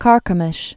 (kärkə-mĭsh, kär-kēmĭsh)